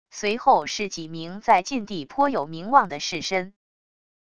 随后是几名在晋地颇有名望的士绅wav音频生成系统WAV Audio Player